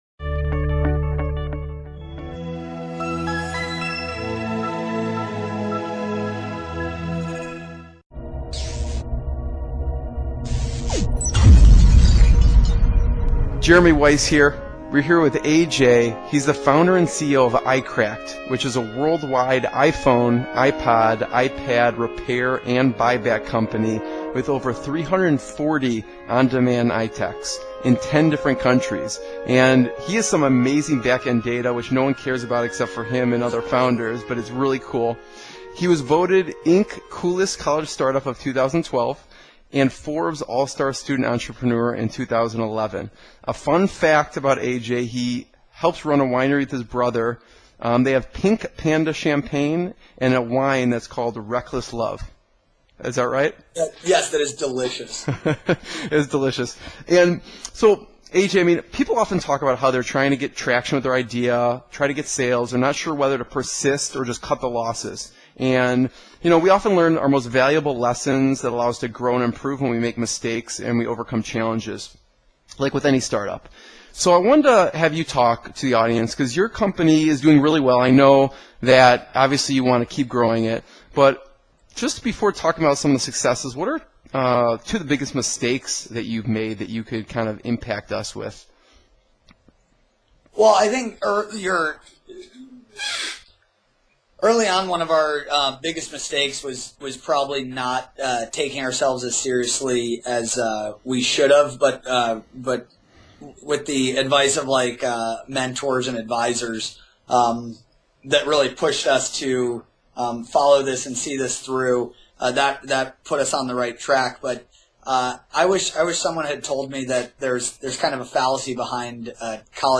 INspired INsider - Inspirational Business Interviews